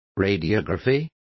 Complete with pronunciation of the translation of radiography.